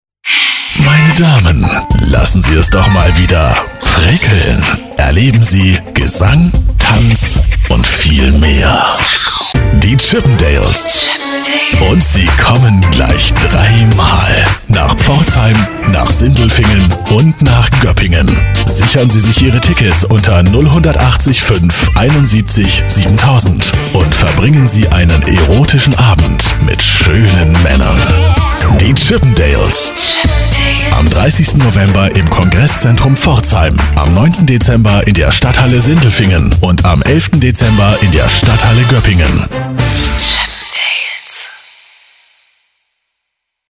Synchronsprecher, Werbesprecher. Tiefe, maskuline Stimme. Sehr variabel, von seriös über freundlich bis angsteinflößend
Sprechprobe: Industrie (Muttersprache):